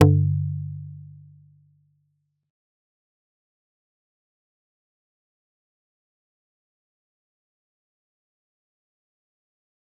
G_Kalimba-G2-mf.wav